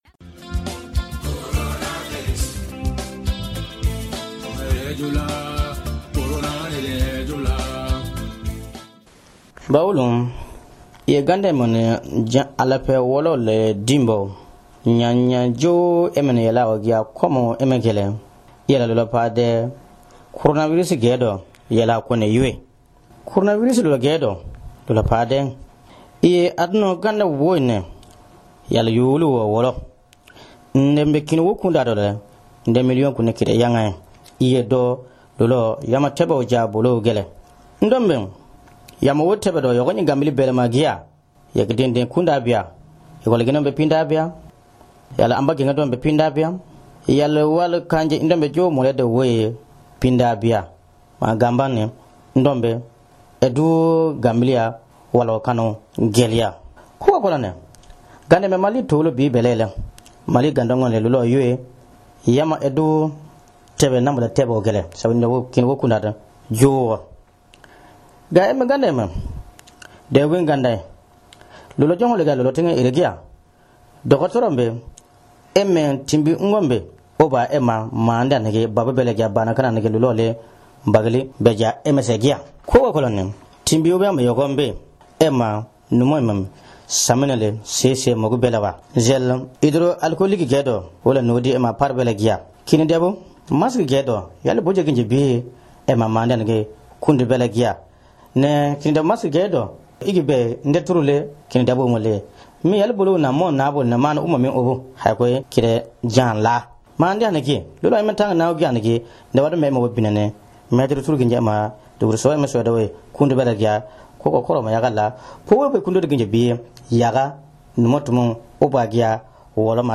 Message préventif diffusé pendant la pandémie de Covid-19 dans lea région de Bandiagara Mali).
Radio_CORONAVIRUS-YCID.mp3